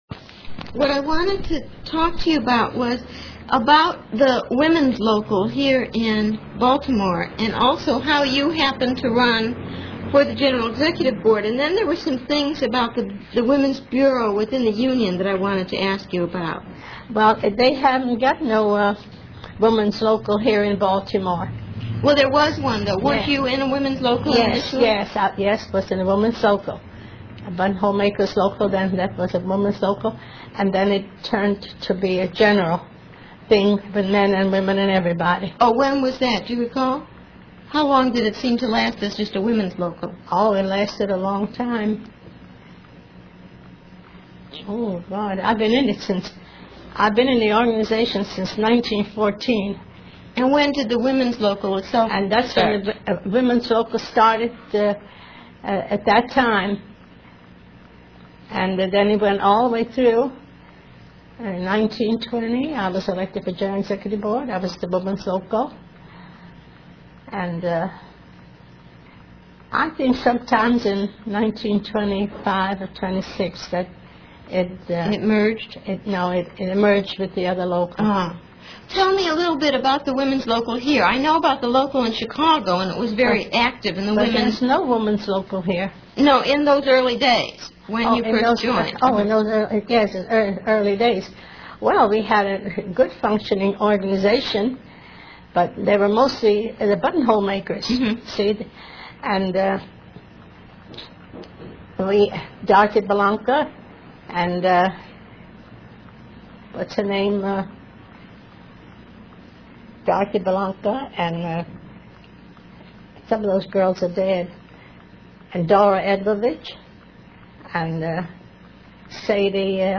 audio interview #2 of 2
the second one was again conducted in her small, neat bungalow in a working class neighborhood of Baltimore.